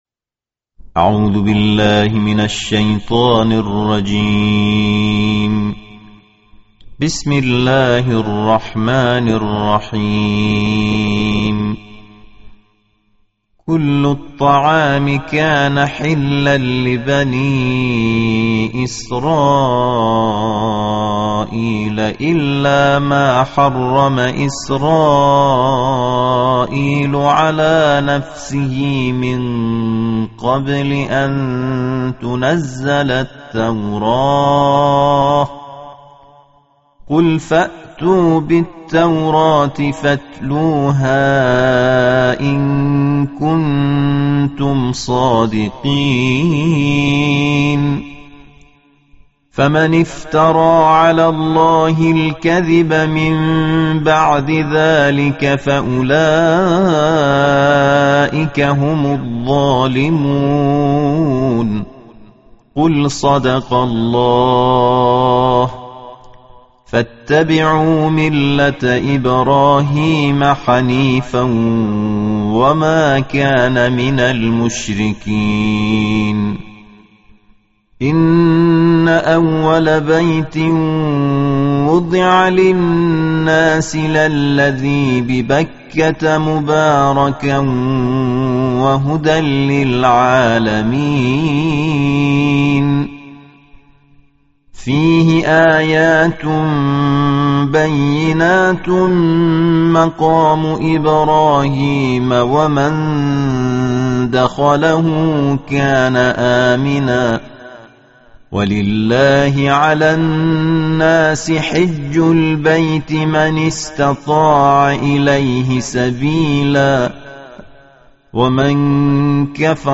Bacaan Tartil Juzuk Keempat Al-Quran